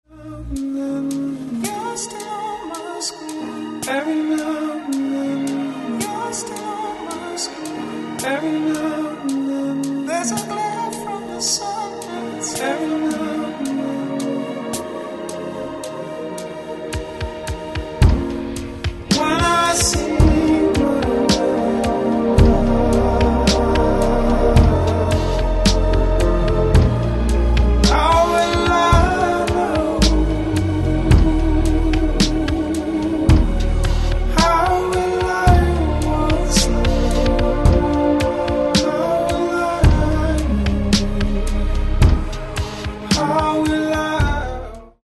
Genre : Electronic